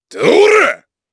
Chase-Vox_Attack2_jp.wav